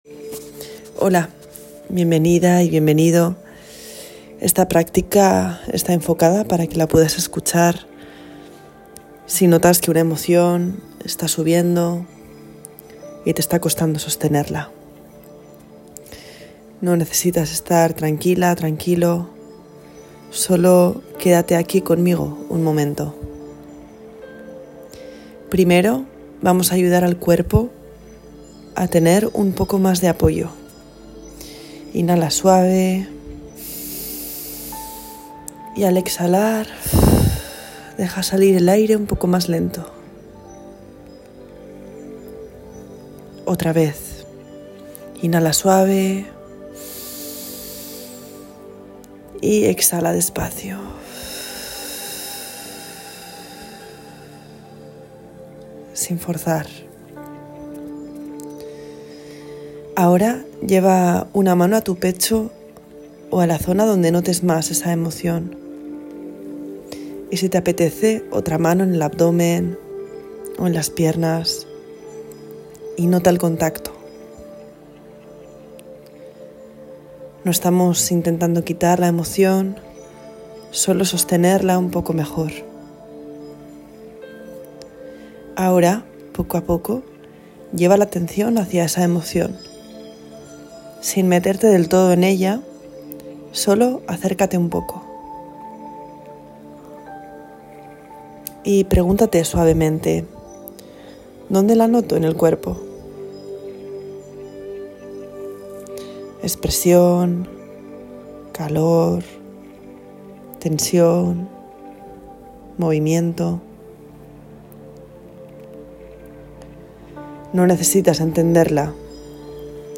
He grabado una práctica pensada para esos momentos en los que la emoción sube y cuesta sostenerla.